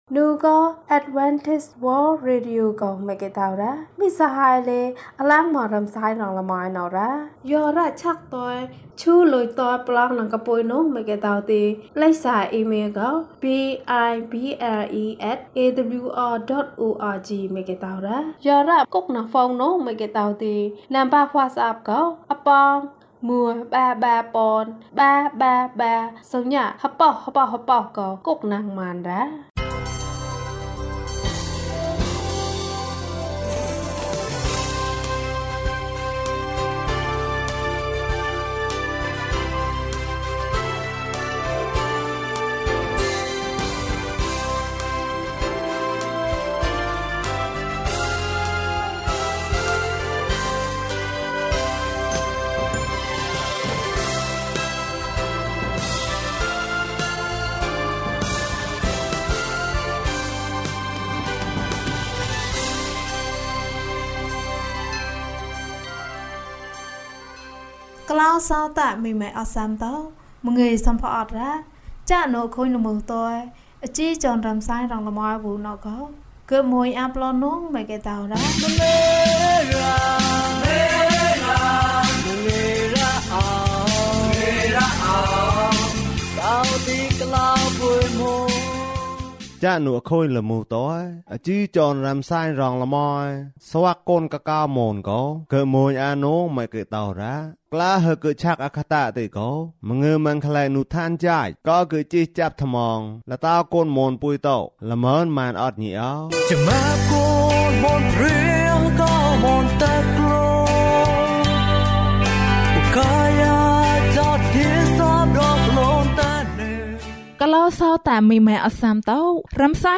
သင်၏ဘုရားသခင်ကို ရိုသေပါ။ ကျန်းမာခြင်းအကြောင်းအရာ။ ဓမ္မသီချင်း။ တရားဒေသနာ။